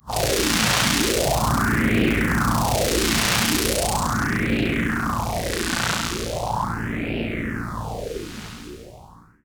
Roland E Noises
Roland E Noise 14.wav